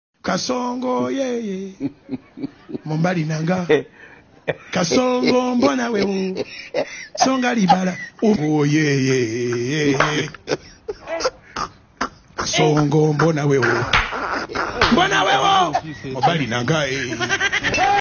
Kasongo yeye (Comedy Sound effect)
Kasongo-yeye-Comedy-Sound-effect.mp3